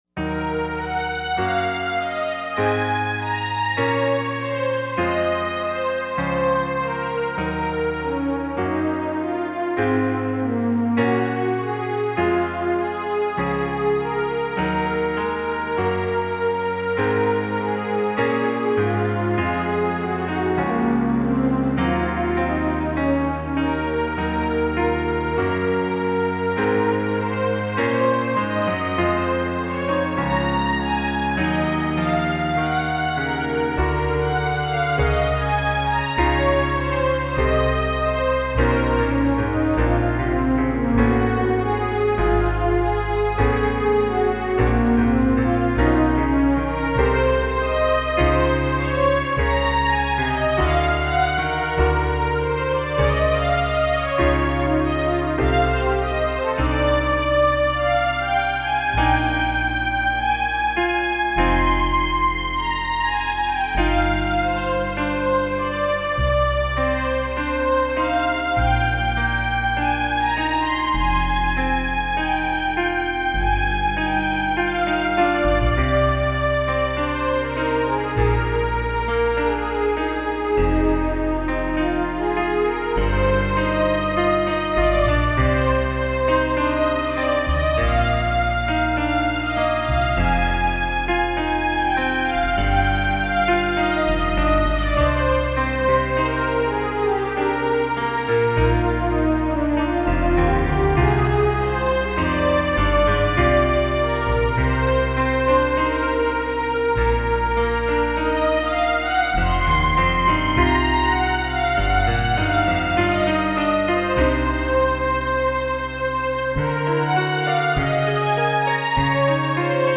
Keyboards and synthesizers.